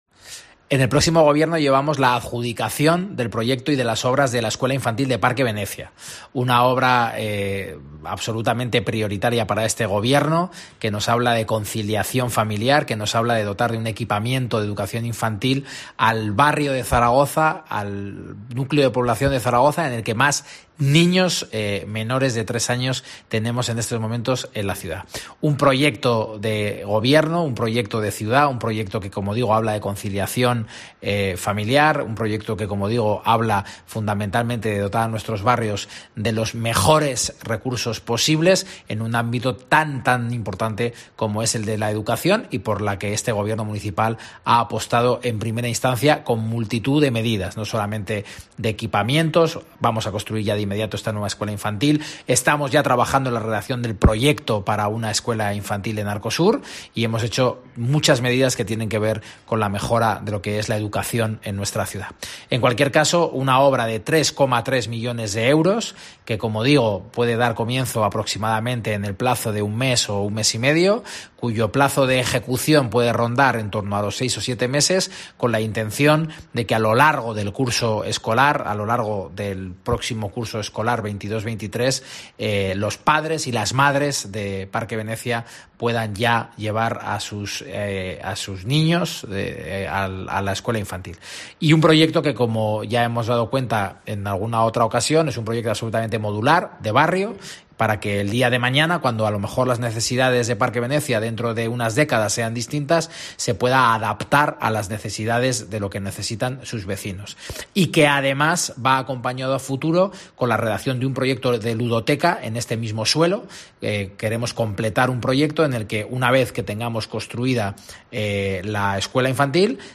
El consejero de Urbanismo, Víctor Serrano, explica los detalles de la nueva Escuela Infantil de Parque Venecia